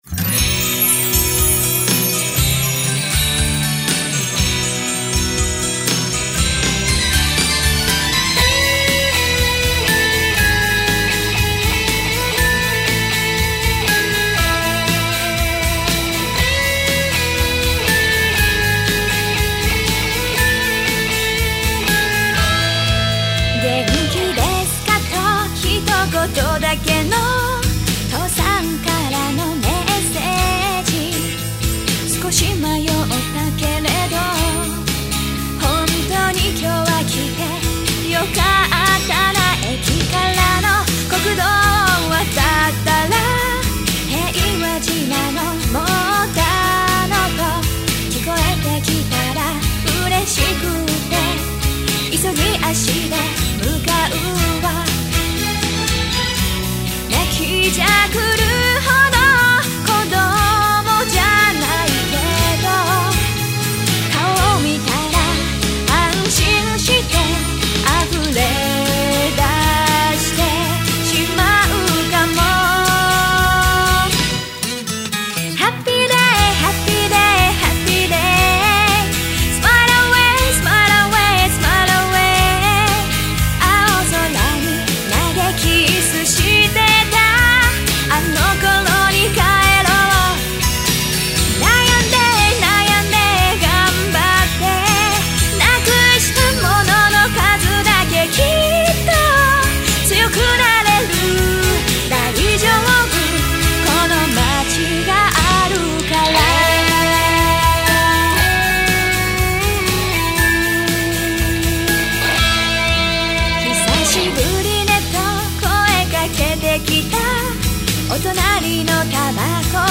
ジャンル：POPS　※非売品